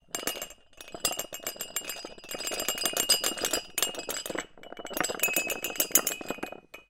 家居用品 " 杯子碗碟 厨房关闭 嘎吱嘎吱 摇晃 叮叮当当 地震 变化 M
描述：各种杯子像地震一样嘎嘎作响。 Sennheiser MKH416进入Zoom H6。 XY立体声中的更远也可以变化。
Tag: 厨房 地震 摇晃 杯子 关闭 霍霍 地震 餐具 叮叮当当 崩溃 VARI 猎枪